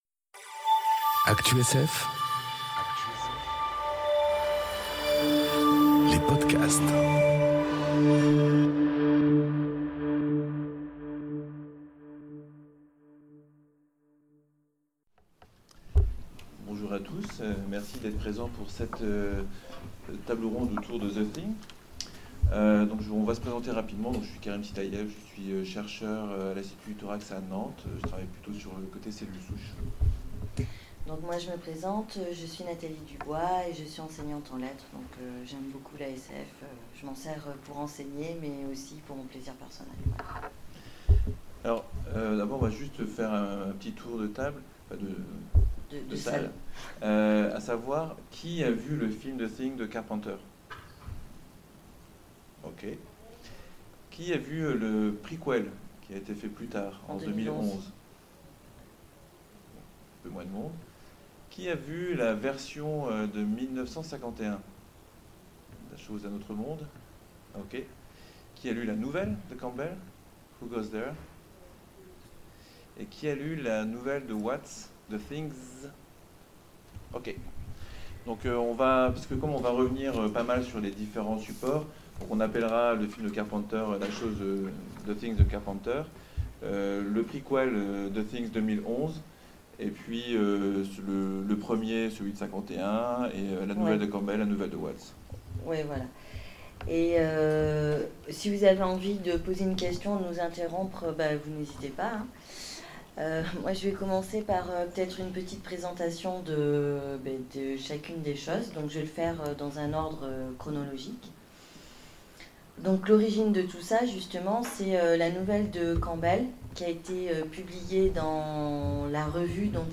réécoutez la conférence Personne ne fait confiance à personne.